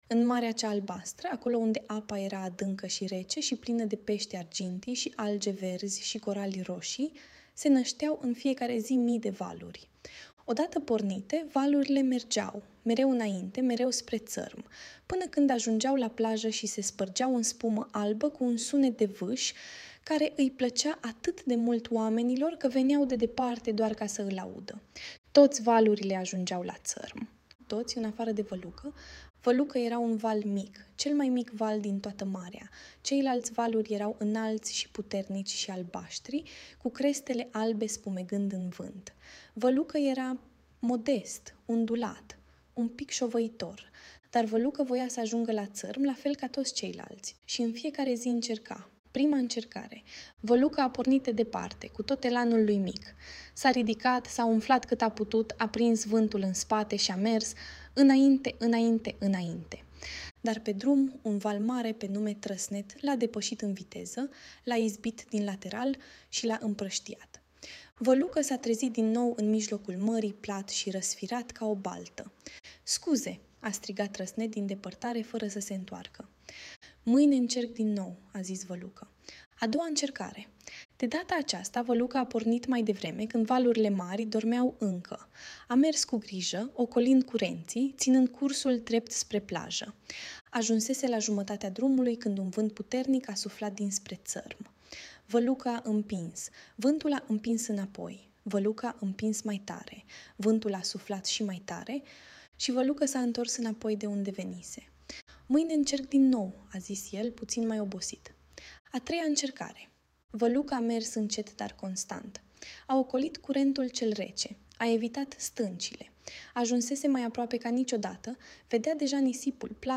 Audiobook Valul cel mic care nu ajungea la țărm
Audiobook-Valul-cel-mic-care-nu-ajungea-la-tarm.mp3